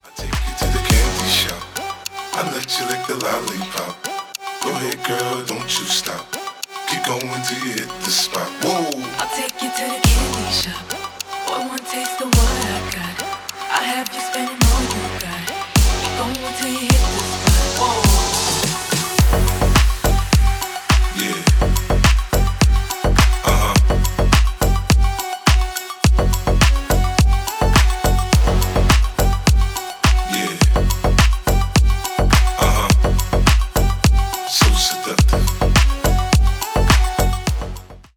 Ремикс # Рэп и Хип Хоп
тихие